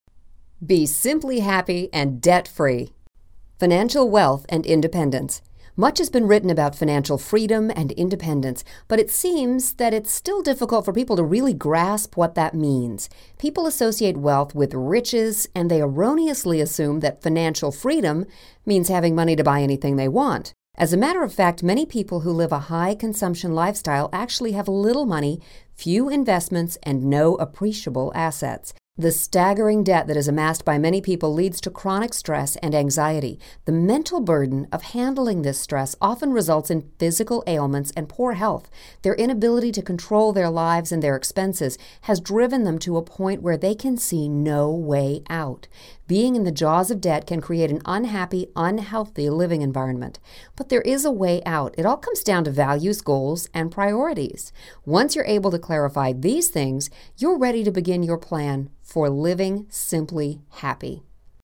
Simply Living Debt Free Audio Book